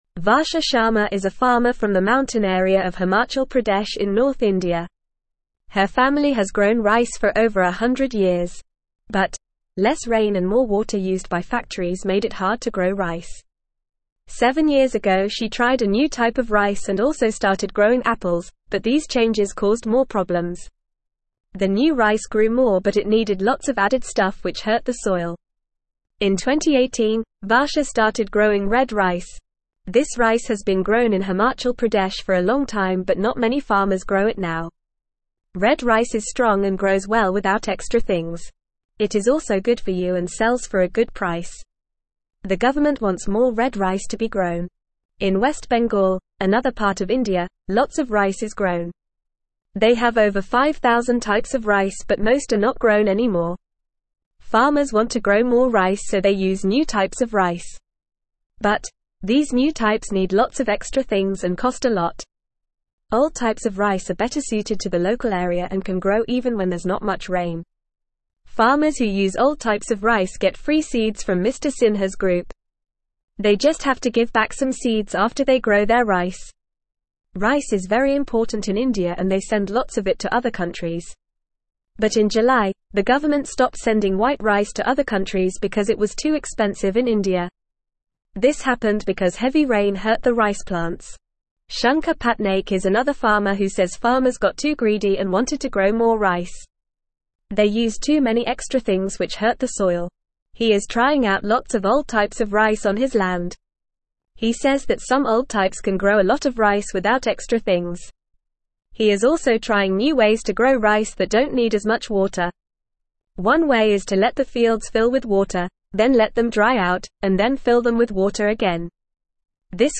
Fast
English-Newsroom-Beginner-FAST-Reading-Indian-Farmers-Try-New-Ways-for-Rice.mp3